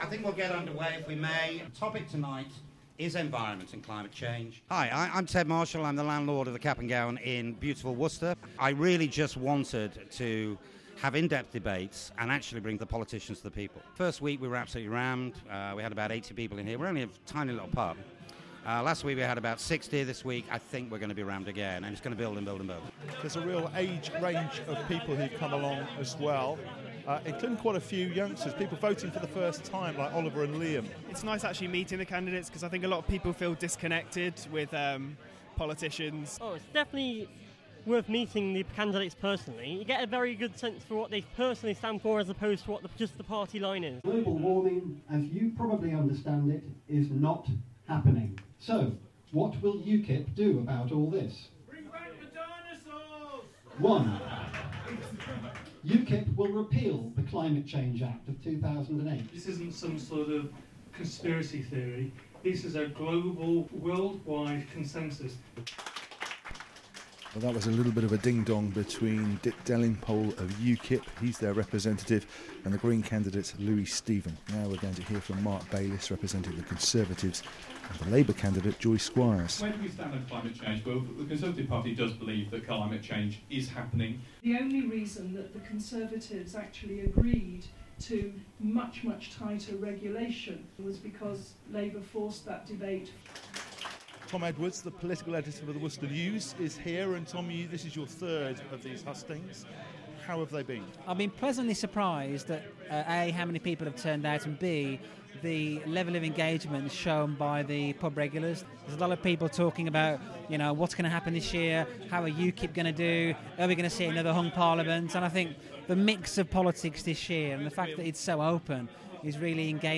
A Pub in Worcester is holding election hustings every Monday night with all the parties taking part. The Cap'n'Gown thinks it will increase political engagement.
On the night I went the Lib Dem representative was ill, so you'll hear from Con, Lab, UKIP & Green.